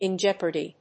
アクセントin jéopardy